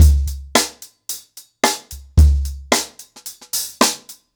HarlemBrother-110BPM.9.wav